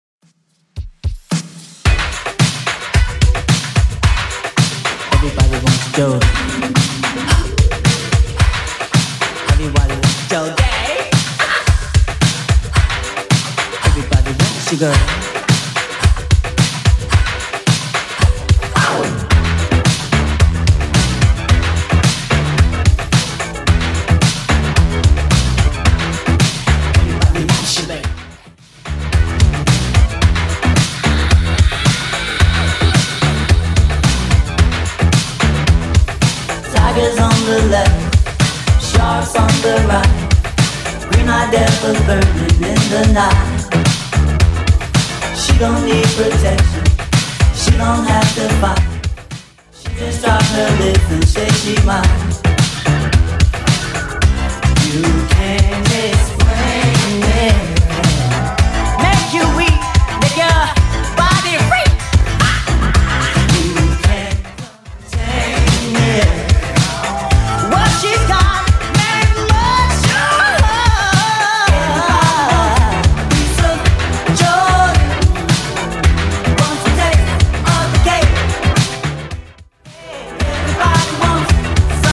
Genre: 80's Version: Clean BPM: 112